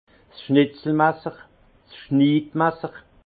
Ville Prononciation 68 Munster